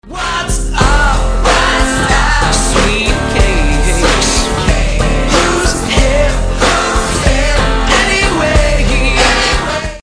MOVIE Opening Theme Song